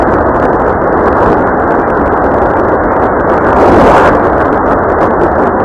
March 19, 2004           Io-B         0233 UT         20.1 MHz
Specimen slowed to 1/2 speed
On several occaisions during Io-B storms I have heard an interesting burst phenomenon which to me makes a sort of "whipping sound".
This sound can be heard at the 3.5 second to 4.2 second interval in the sound file on the chart above.